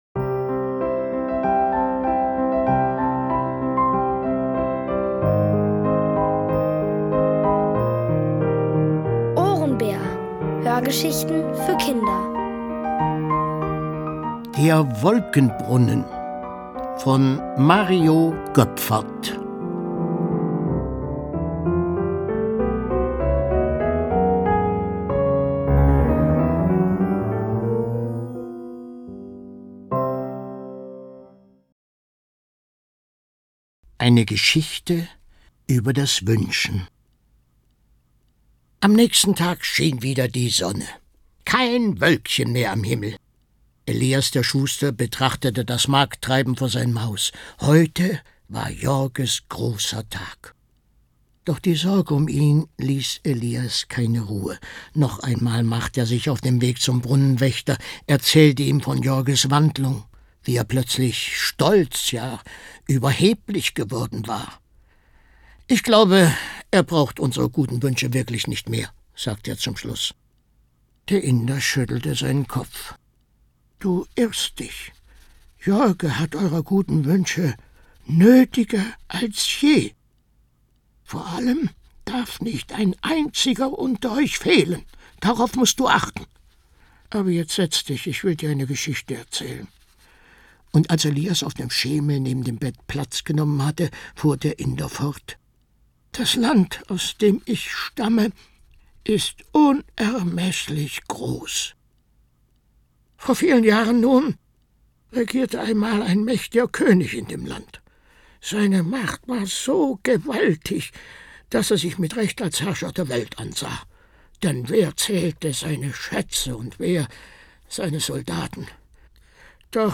Von Autoren extra für die Reihe geschrieben und von bekannten Schauspielern gelesen.
Es liest: Horst Bollmann.